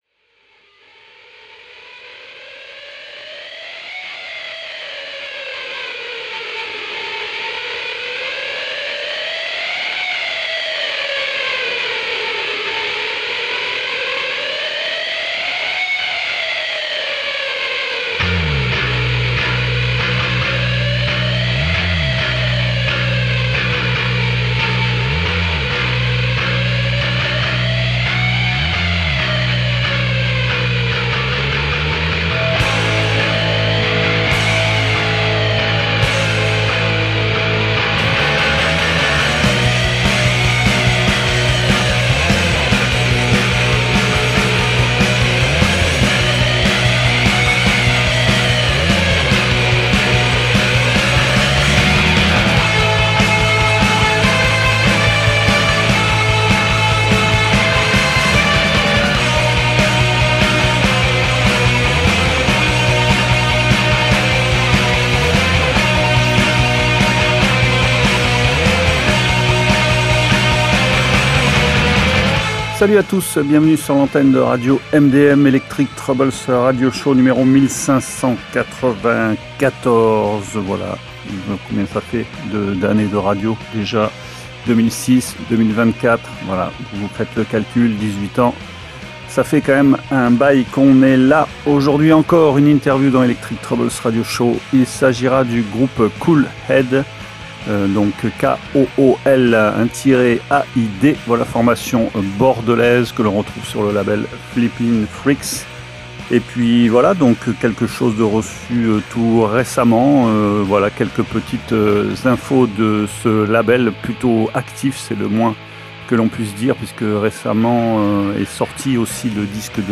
Une sorte de monde parallèle plutôt bruyant et luisant.